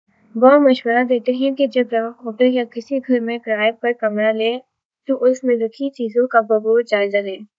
deepfake_detection_dataset_urdu / Spoofed_Tacotron /Speaker_16 /116.wav